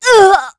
Isolet-Vox_Damage_kr_03.wav